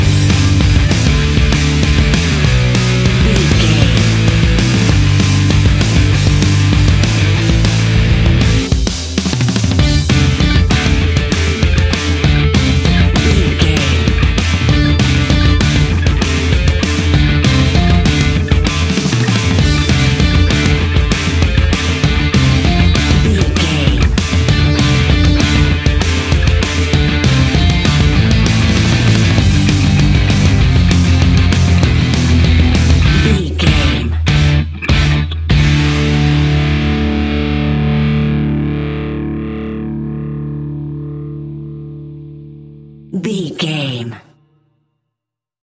Uplifting
Fast paced
Ionian/Major
Fast
punk metal
Rock Bass
Rock Drums
distorted guitars
hammond organ